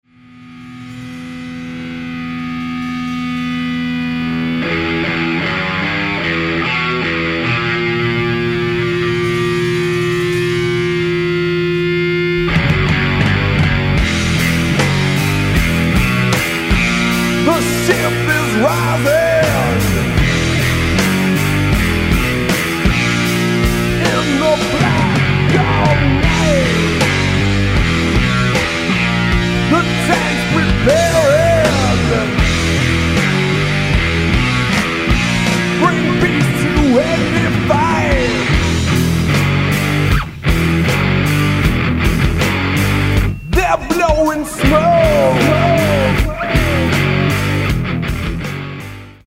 BASS & VOCALS
GUITAR
DRUMS